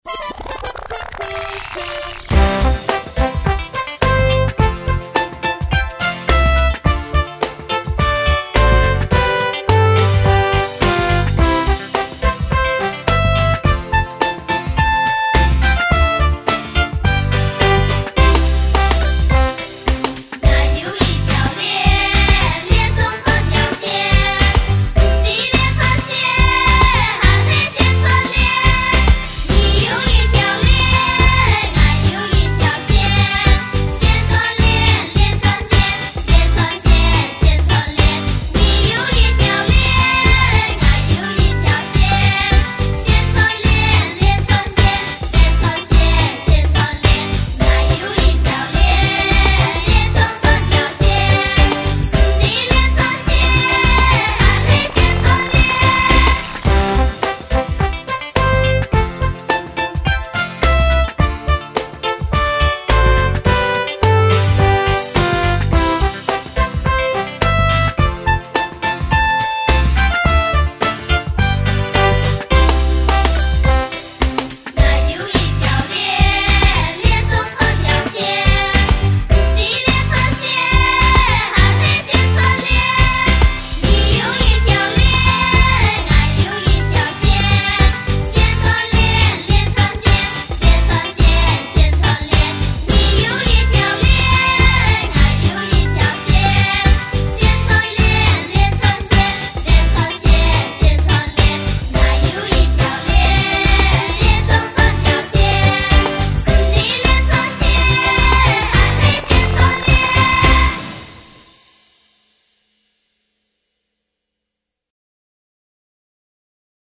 繞口令(完整演唱版) | 新北市客家文化典藏資料庫